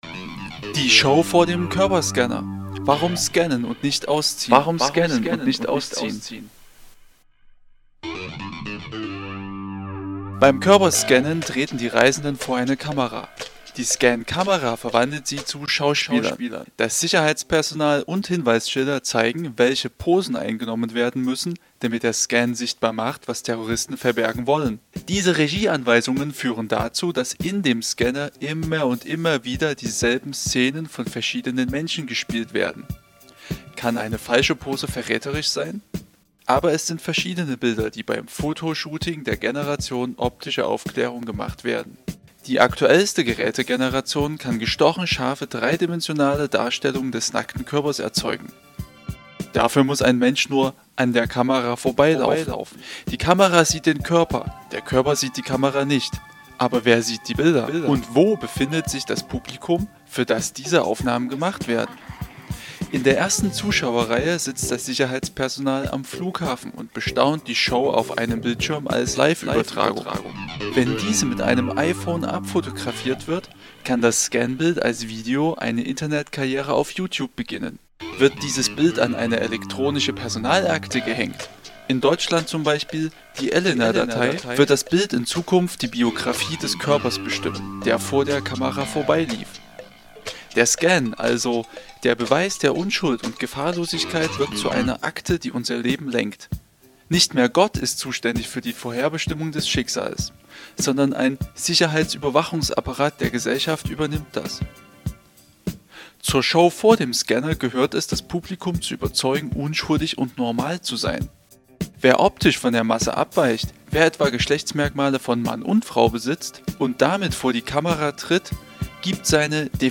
Musik: Zwei Opensource Tracks:
1. eine sehr gelungene audio-performance. zu beginn machst du aus dem nacktscan ein schauspiel aus schauspielern und publikum. das ist eine feine entfremdung, eine beschreibung des vorgangs als ritual. das gefaellt.